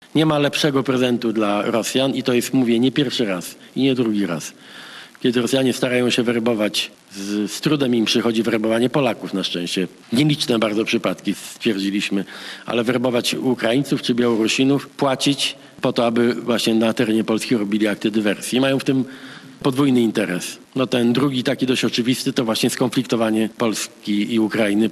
Premier Donald Tusk powiedział. że to kolejny przypadek dywersji w Polsce: